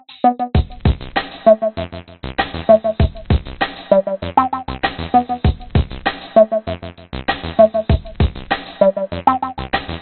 Tag: 155 bpm Dubstep Loops Vocal Loops 1.04 MB wav Key : G